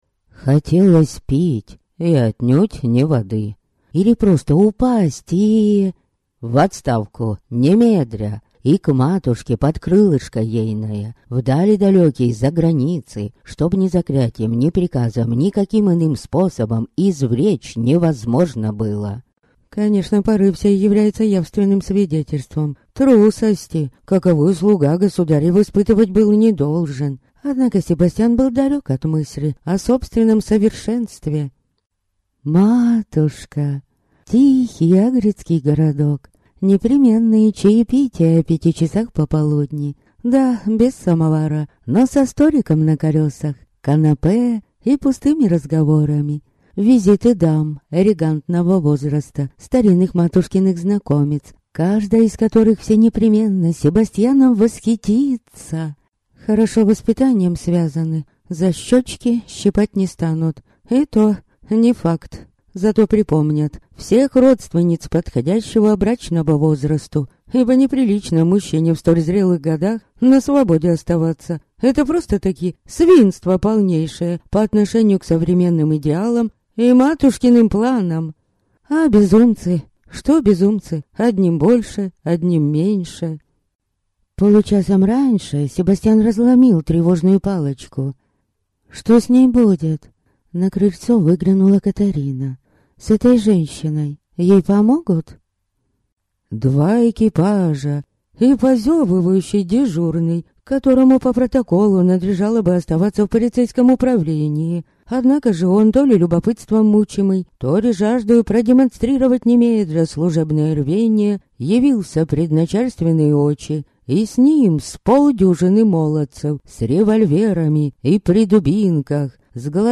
Аудиокнига Ловец бабочек. Книга 2. Мотыльки (часть 2) | Библиотека аудиокниг